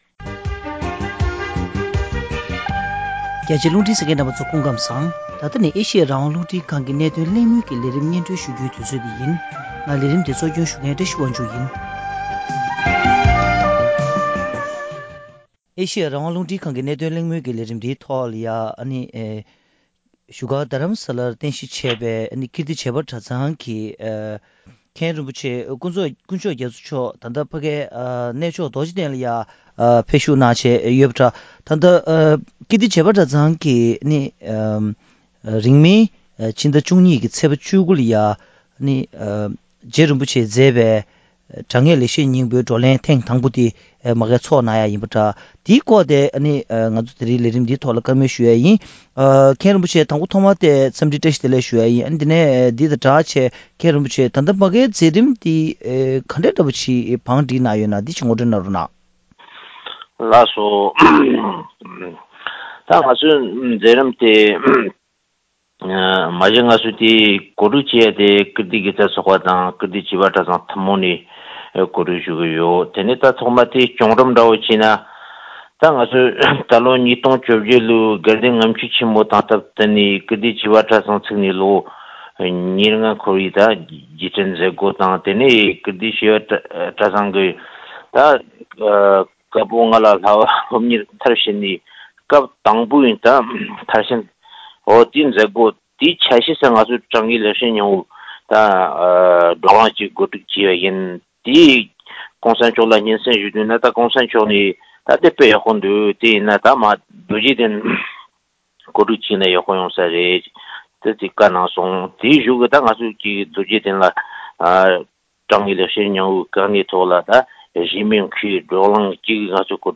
༄༅། །གནད་དོན་གླེང་མོལ་གྱི་ལས་རིམ་ནང་།